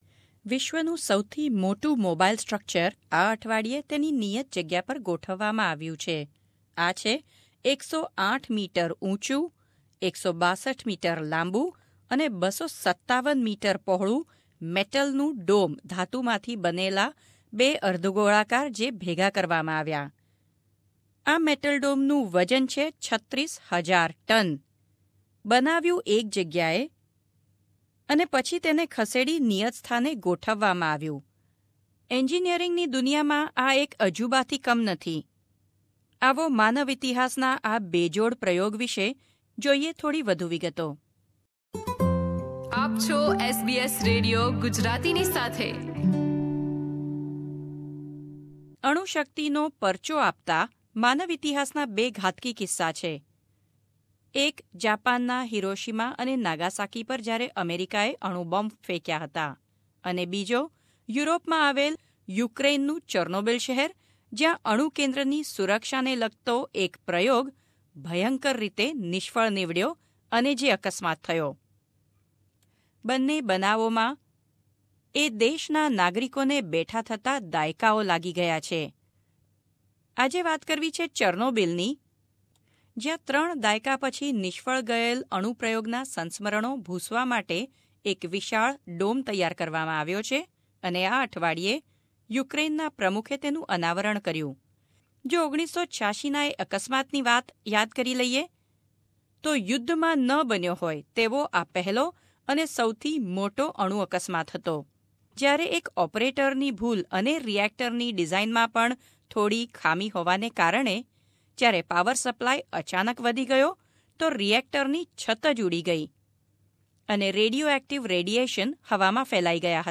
reports on the shelter that promises to provide radiation safety for the next century.